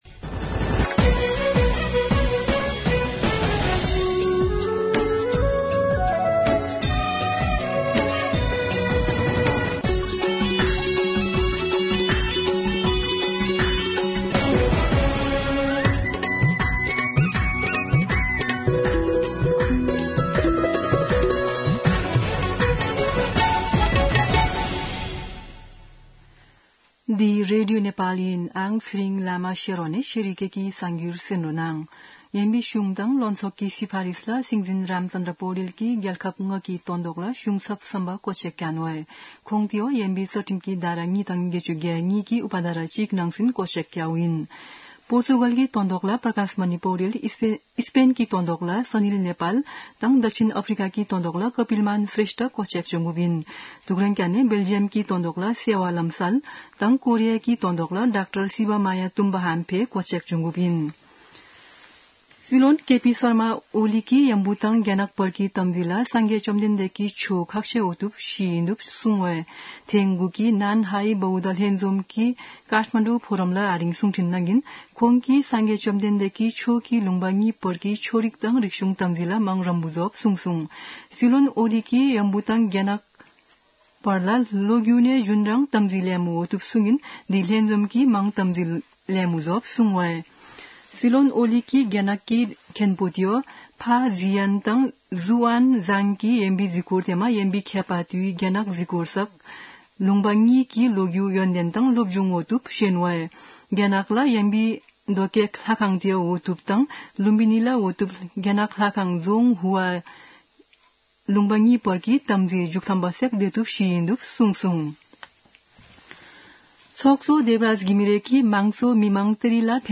शेर्पा भाषाको समाचार : २९ मंसिर , २०८१
Sherpa-news-1.mp3